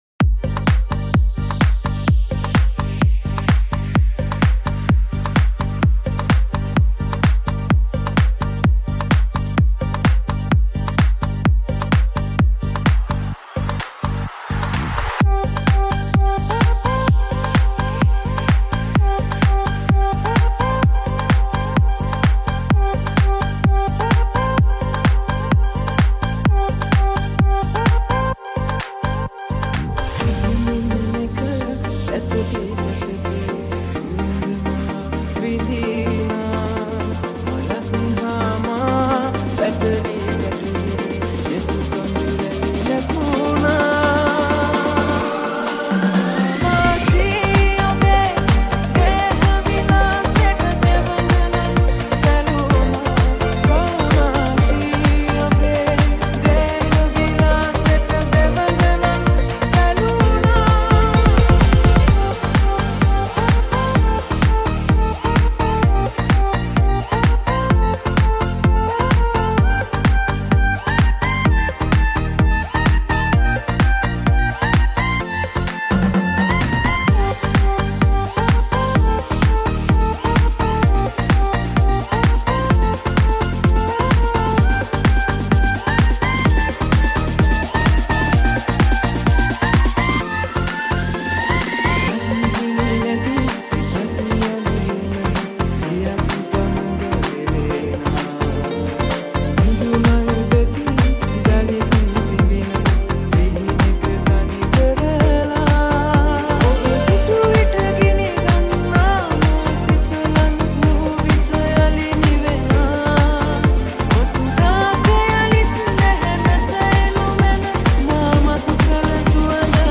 High quality Sri Lankan remix MP3 (4.4).